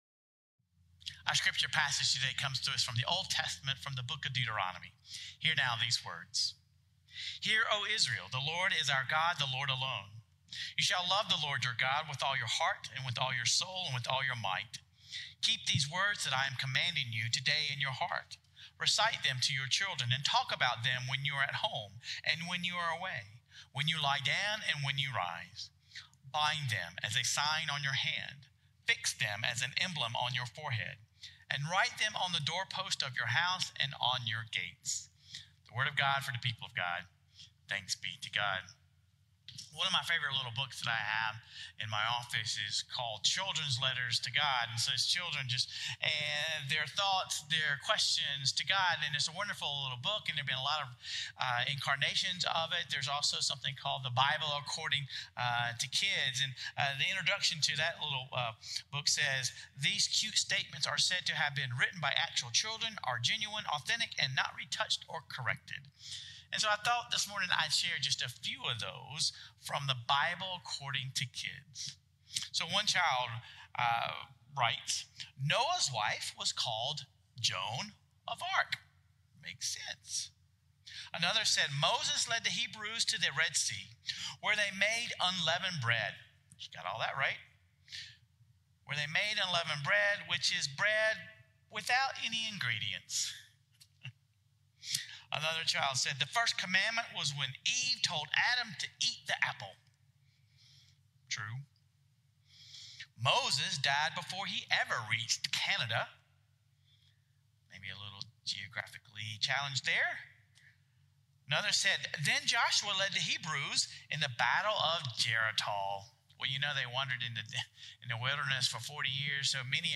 Sermon Reflections: Teaching the faith to our children is essential.